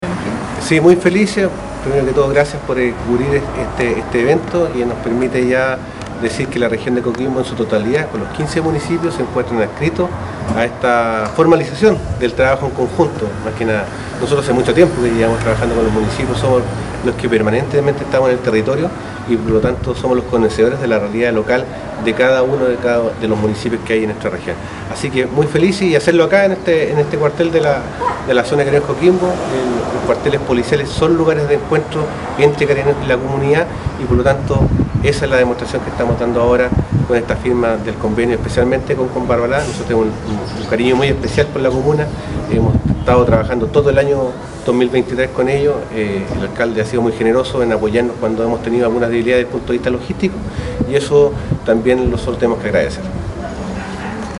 Así lo destacó el Jefe de la Cuarta Zona de Carabineros de Chile, el General Juan Antonio Muñoz Montero :